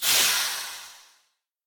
train-breaks-3.ogg